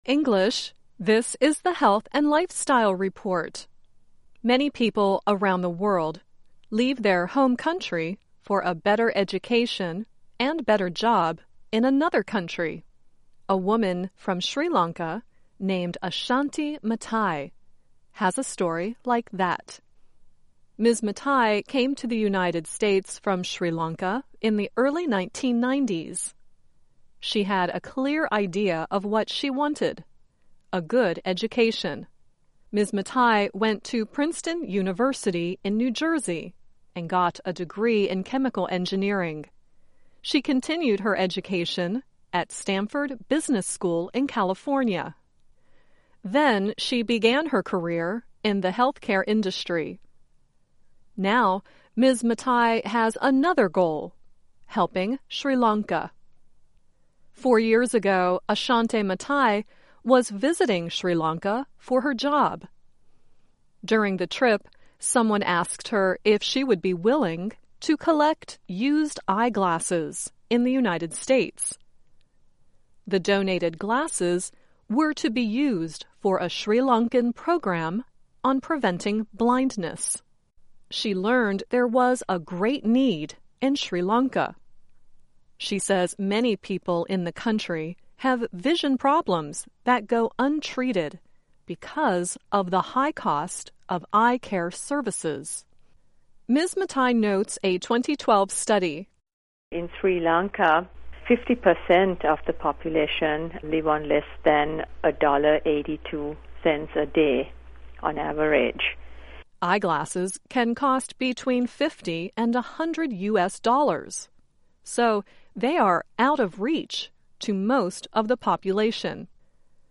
Learn English as you read and listen to a weekly show about developments in science, technology and medicine. Our stories are written at the intermediate and upper-beginner level and are read one-third slower than regular VOA English.